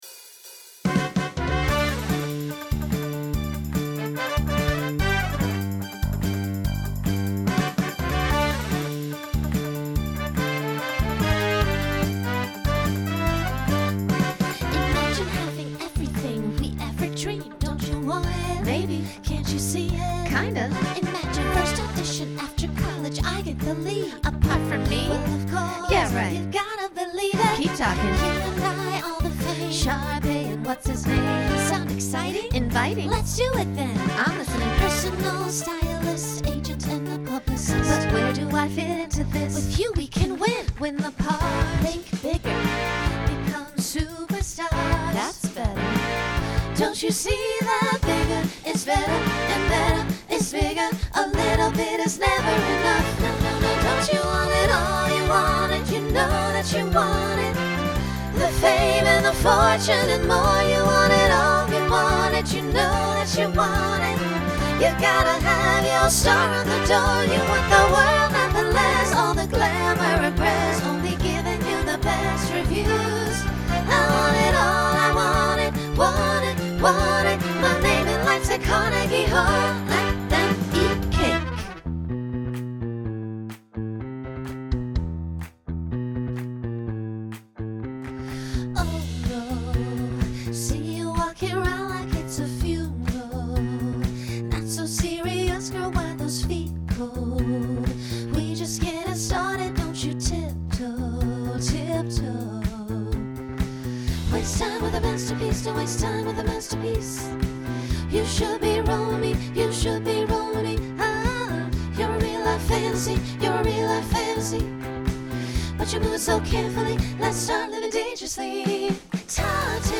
Pop/Dance Instrumental combo
Voicing SSA